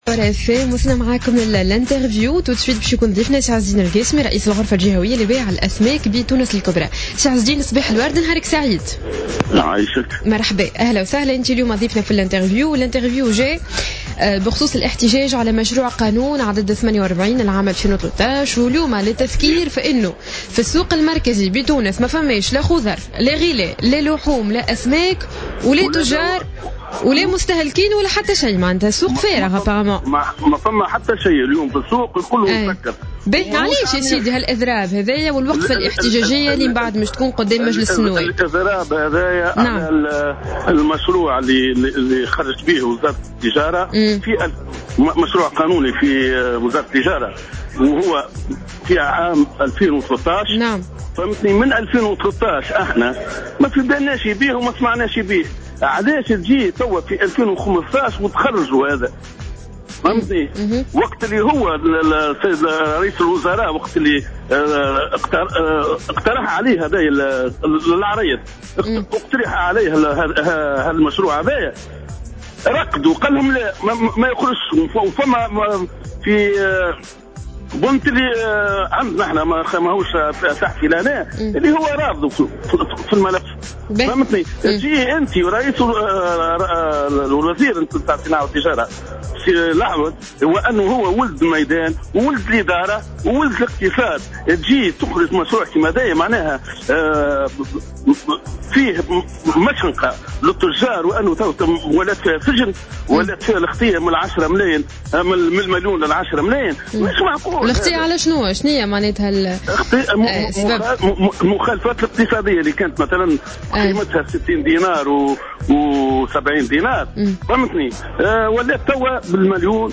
مداخلة على جوهرة "اف ام"